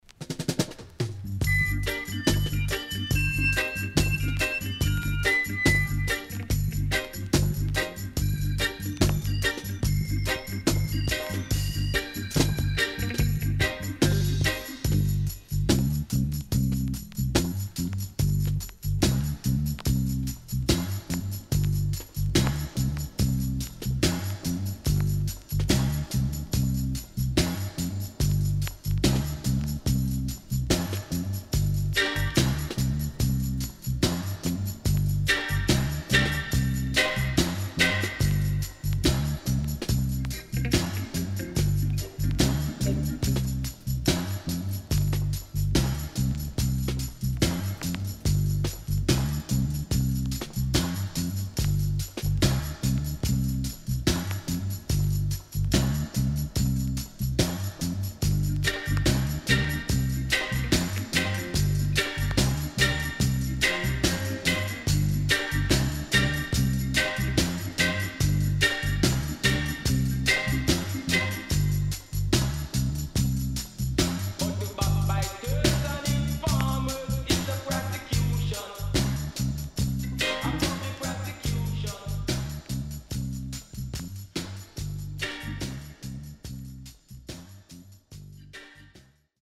HOME > REGGAE / ROOTS
SIDE A:所々プチノイズ入ります。